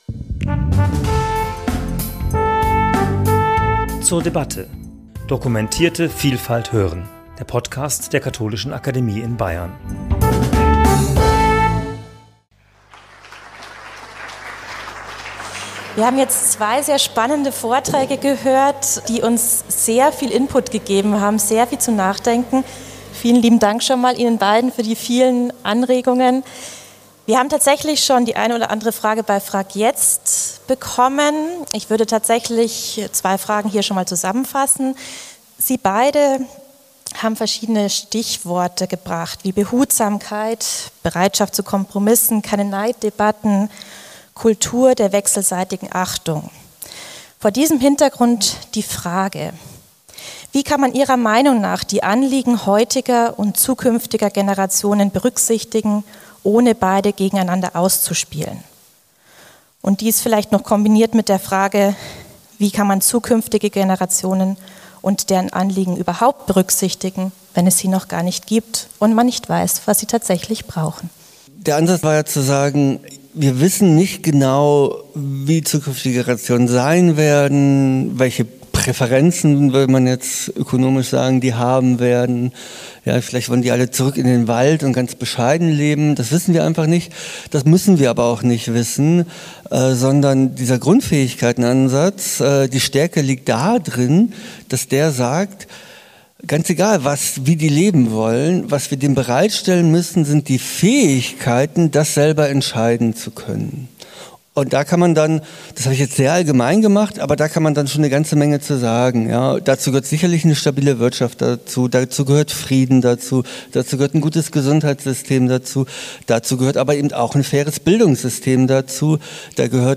Gespräch zum Thema 'Staatsfinanzen und Generationengerechtigkeit' ~ zur debatte Podcast